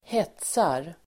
Uttal: [²h'et:sar]